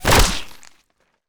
flesh2.wav